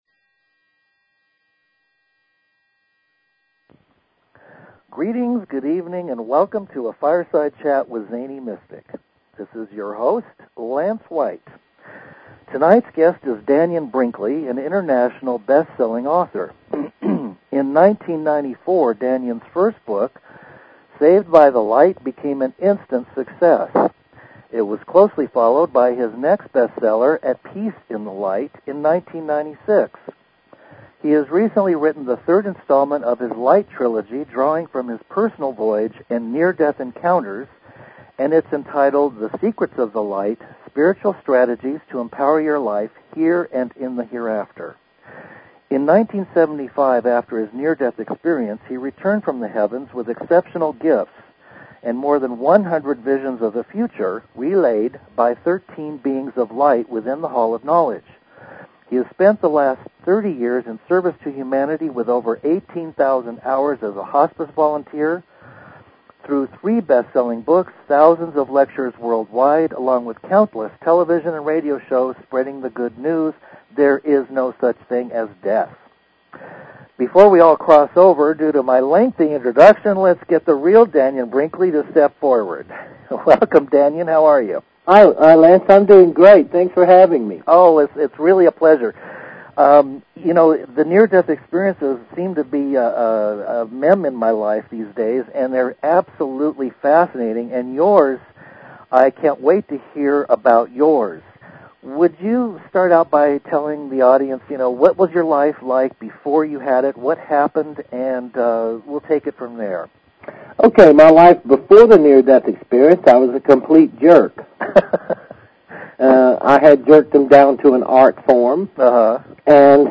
Guest, Dannion Brinkley